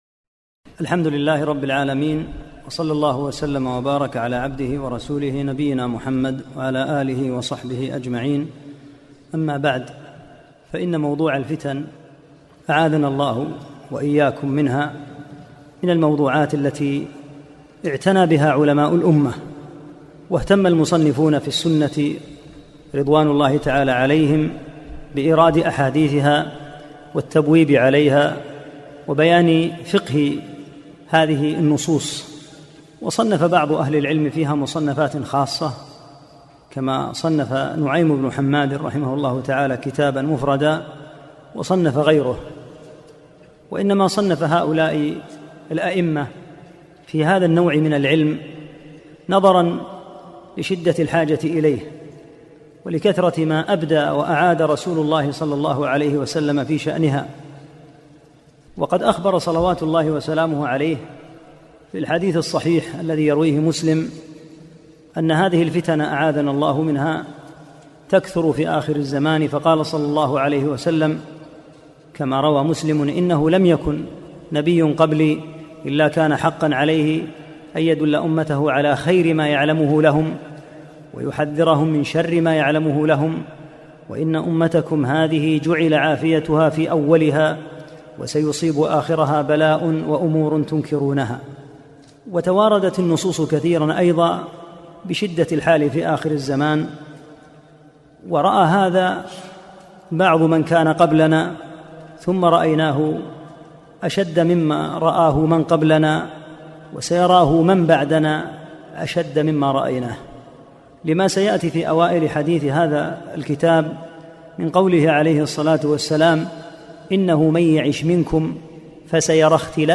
1 - الدرس الأول